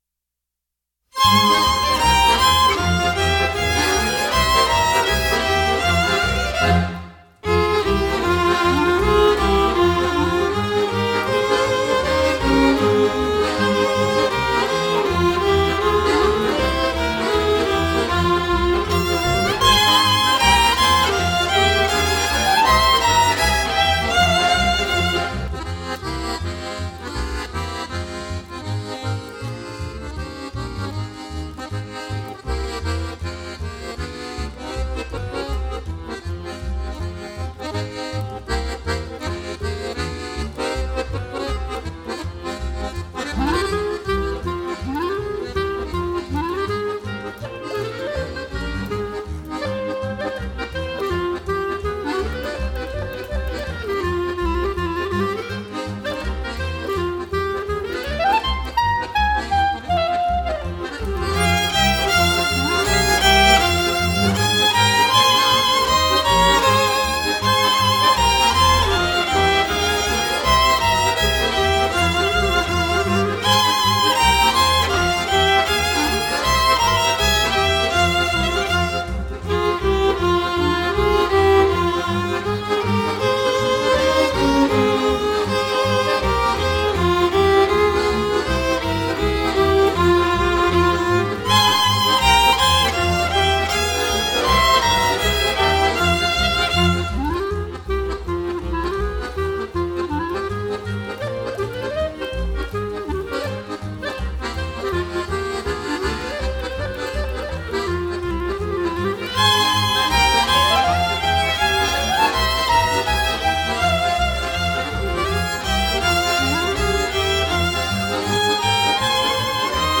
Piesne pre deti a rodinu
kec-sebe-zaspivam-podklad.mp3